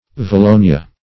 Valonia \Va*lo"ni*a\, n. [It. vallonia, vallonea, fr. NGr.